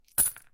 coin.mp3